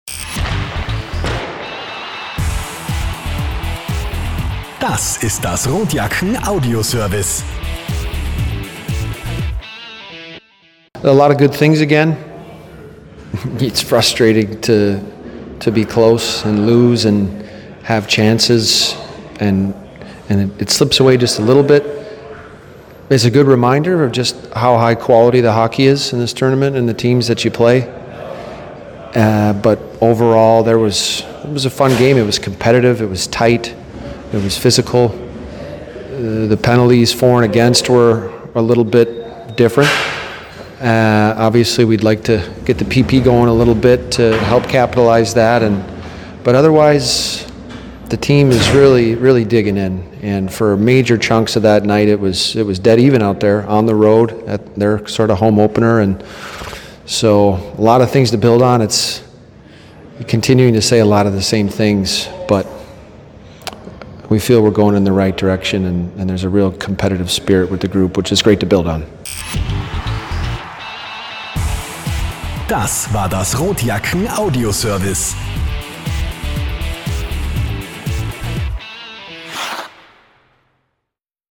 Post Game-Kommentar